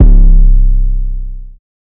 SpinzWeird [808].wav